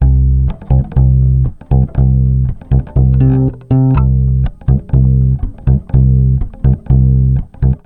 Бас, на сенде делей битвиговский стандартный + эхобой. Эхобой просто красит лентой. 1.